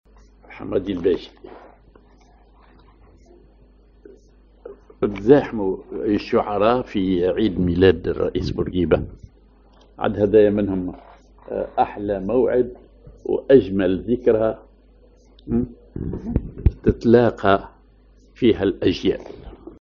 Maqam ar أصبعين
genre أغنية